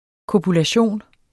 Udtale [ kobulaˈɕoˀn ]